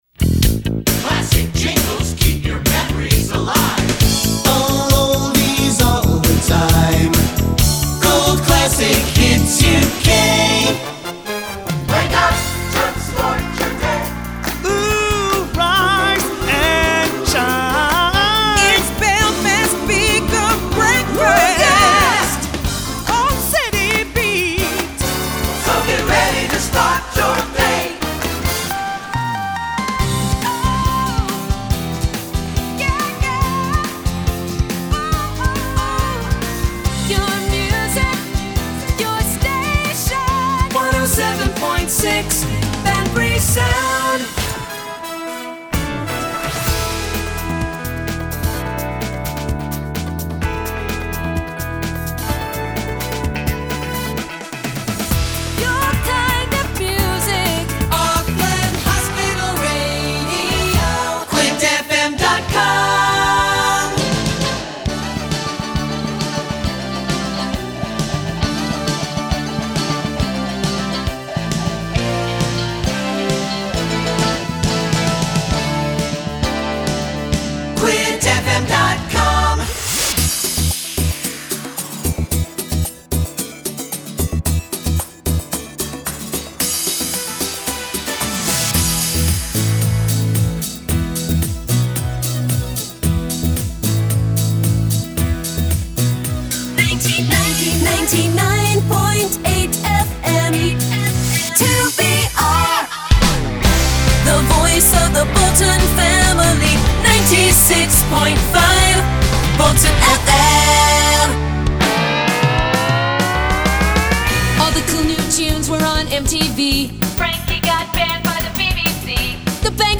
Dit zijn de 3 bijbehorende jingles…